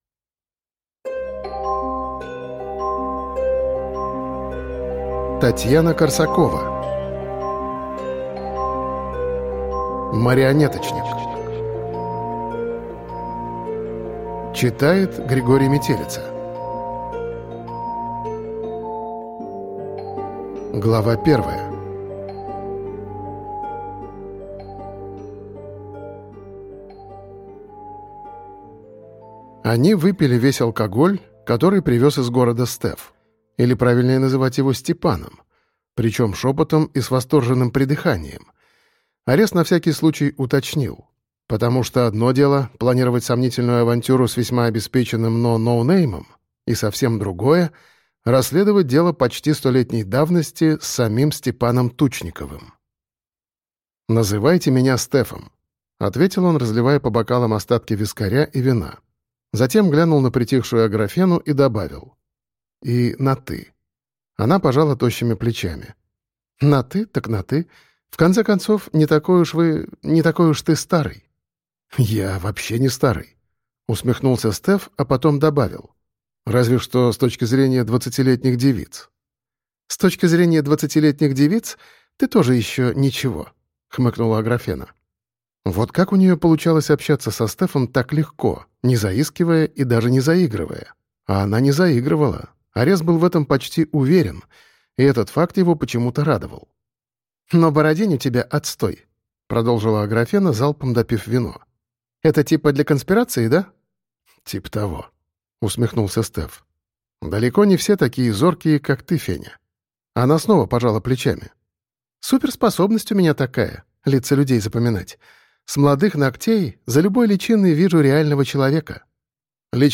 Аудиокнига «Марионеточник».
В книге использованы музыкальные вставки: